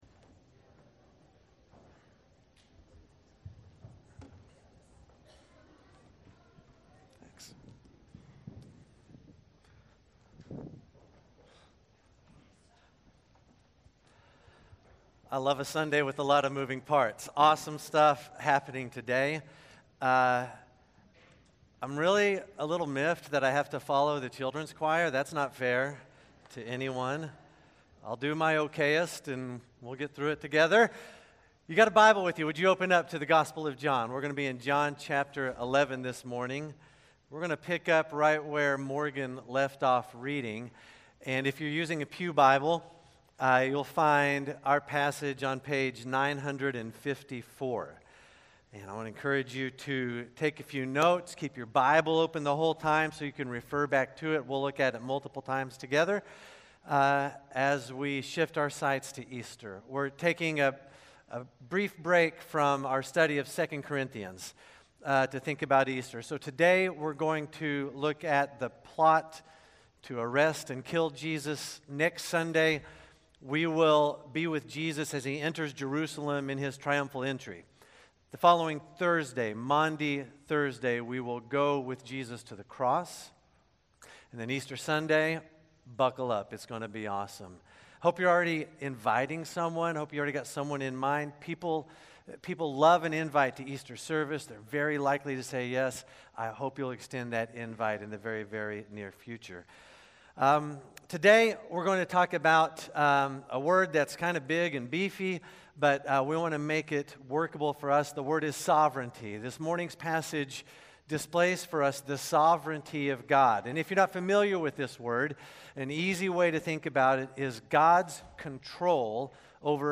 South Shore Baptist Church Sermons